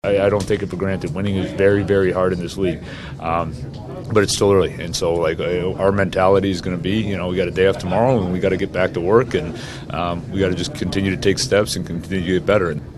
The coach appreciates winning, but continues to emphasize that it’s early in the season and they have to get better every day.